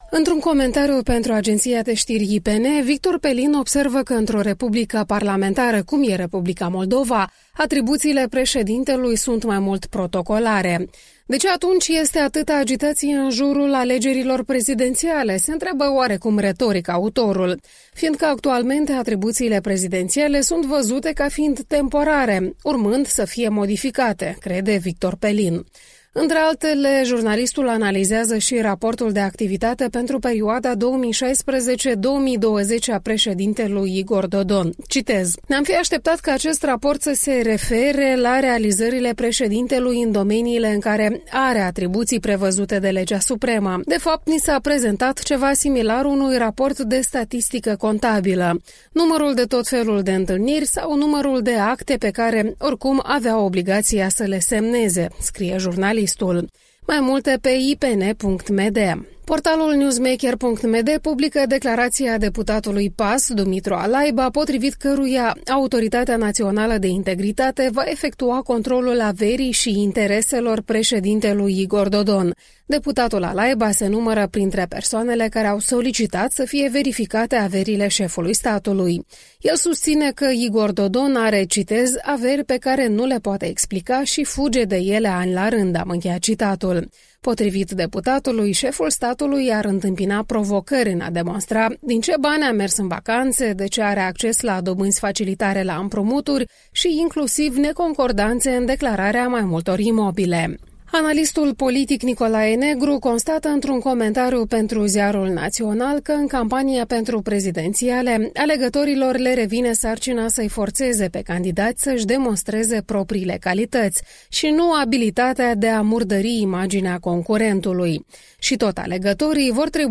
Revista matinală a presei la radio Europa Liberă.